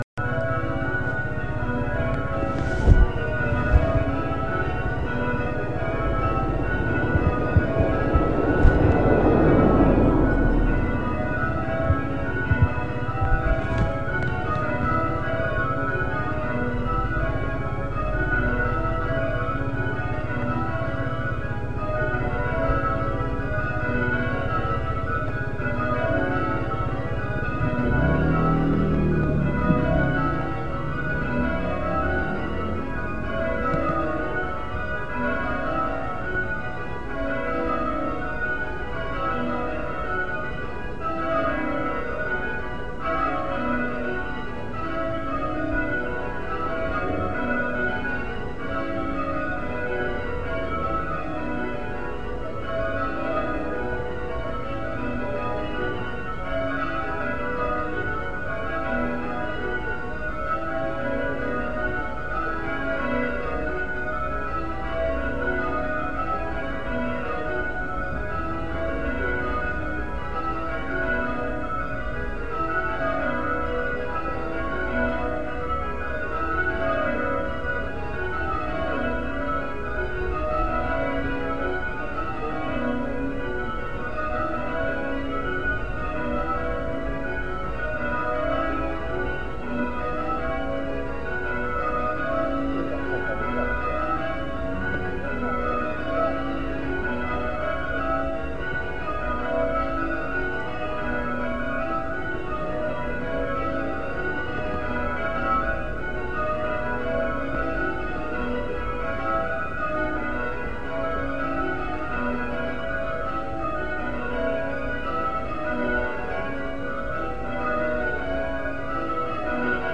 September Old St Martin's Bells 1